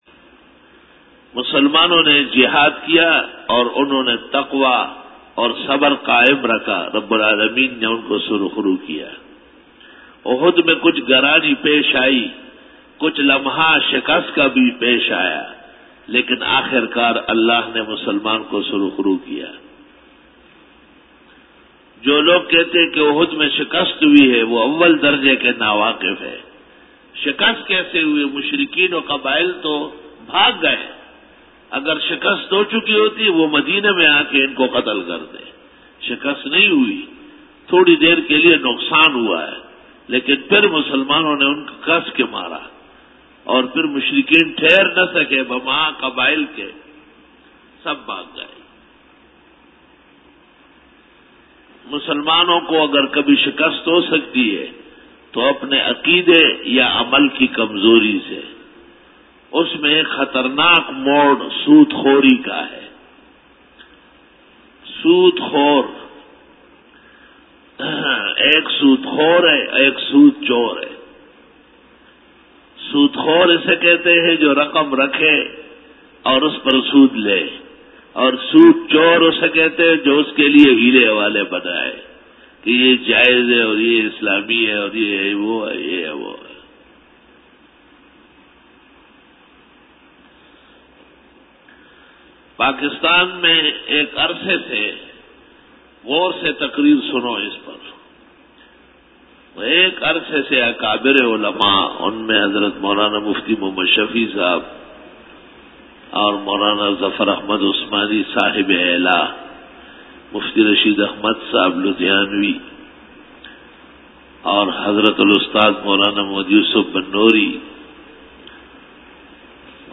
Dora-e-Tafseer 2011